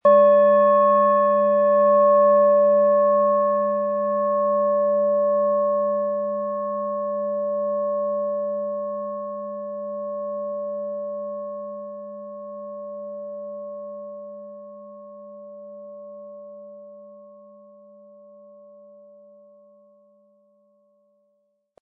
Planetenton
Es ist eine von Hand gearbeitete tibetanische Planetenschale Uranus.
PlanetentonUranus
MaterialBronze